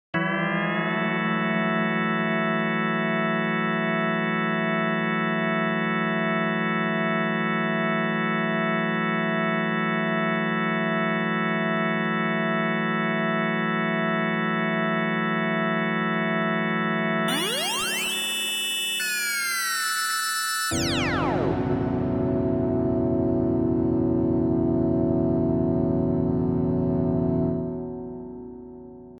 • Качество: высокое
Оглушающий гул инопланетян, впервые ступивших на планету